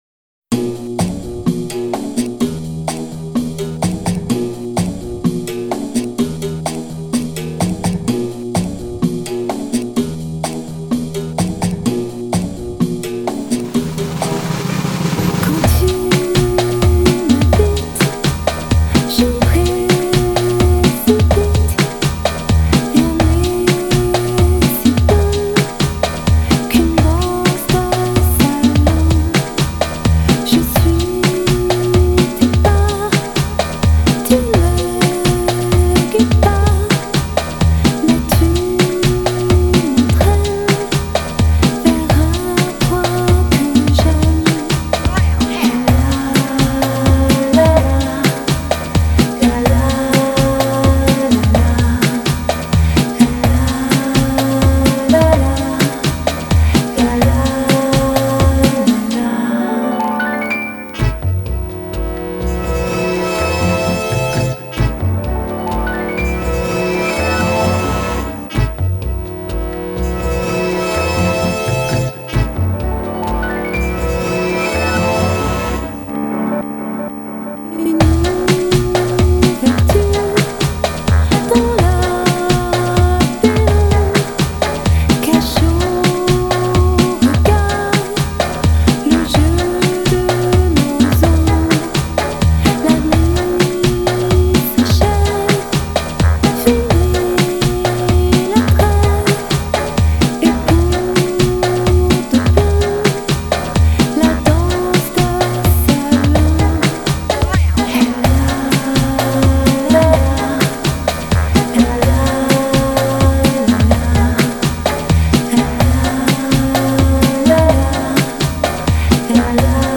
唱片类型：Lounge